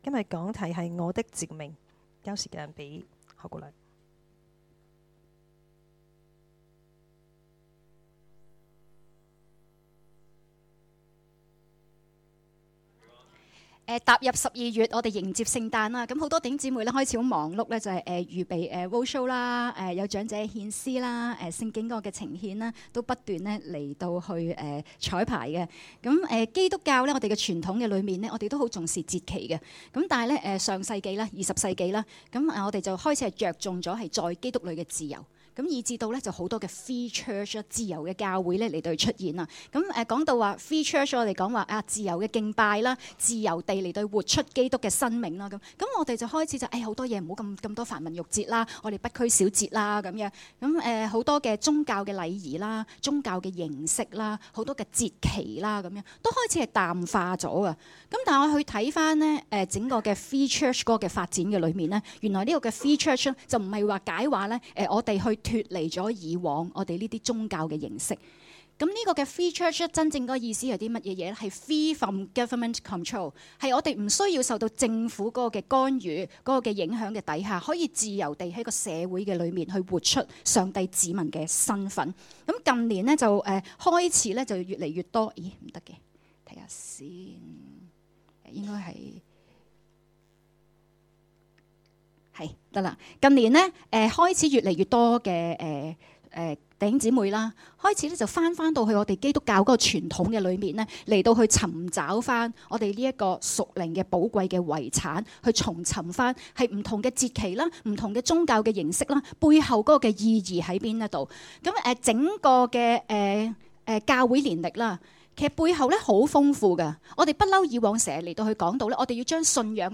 2016年12月17日及18日崇拜